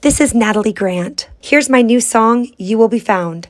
Liners